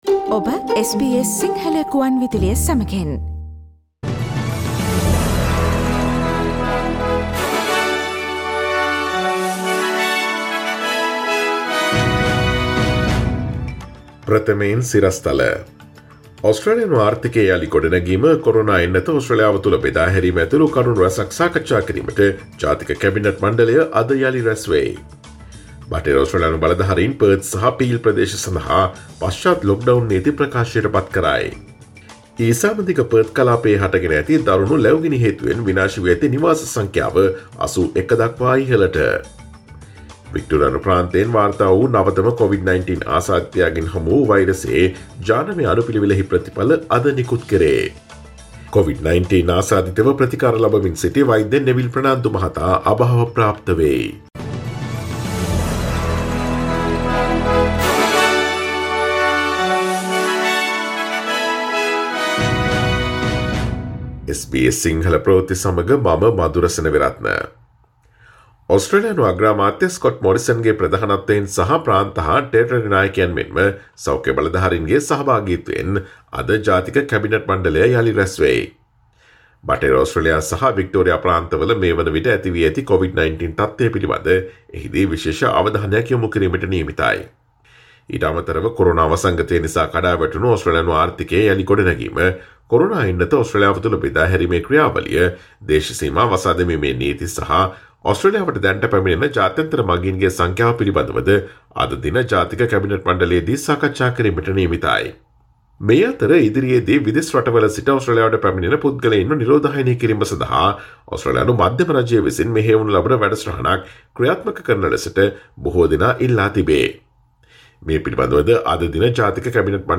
Today’s news bulletin of SBS Sinhala radio – Friday 05 February 2021.